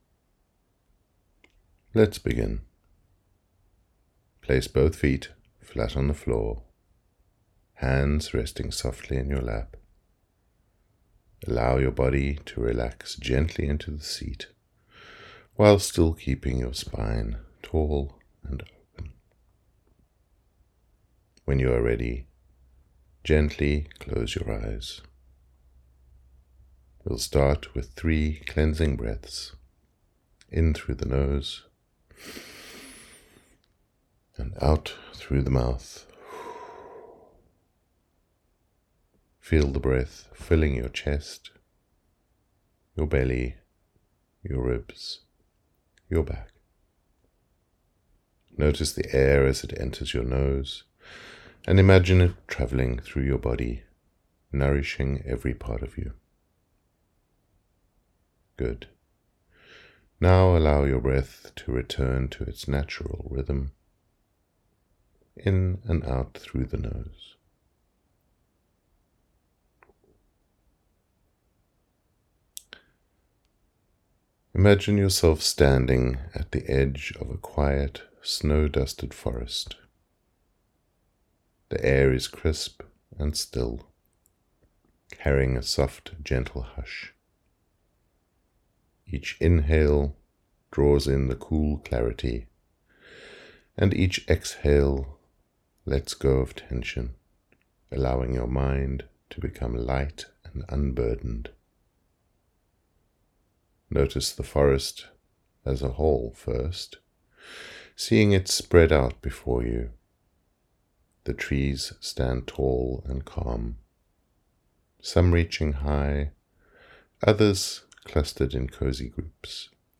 foresT and the Trees Meditation
WV03-meditation.mp3